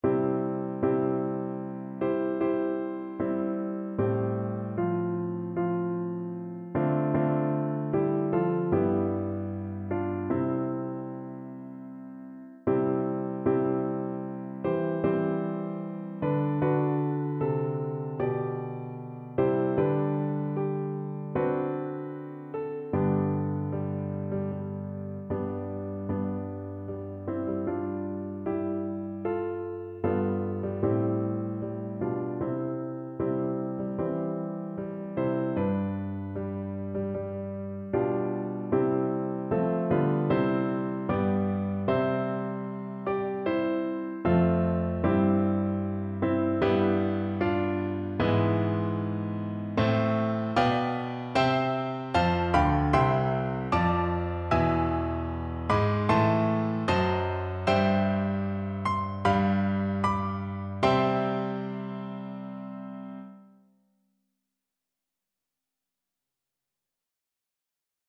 Piano version
No parts available for this pieces as it is for solo piano.
Andantino sostenuto =c.76 (View more music marked Andantino)
4/4 (View more 4/4 Music)
Piano  (View more Easy Piano Music)